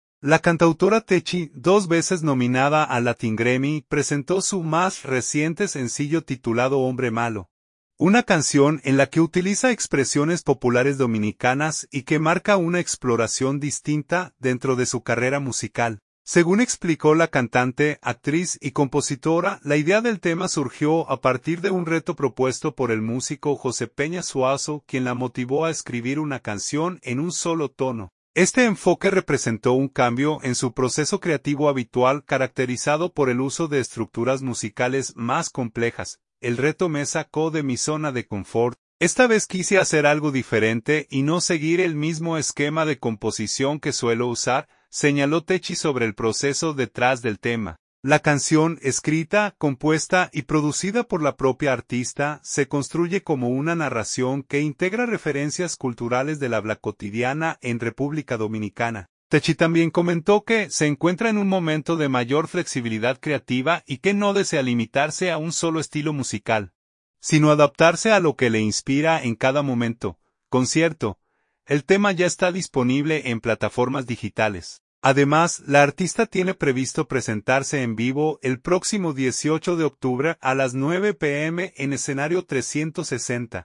quien la motivó a escribir una canción en un solo tono.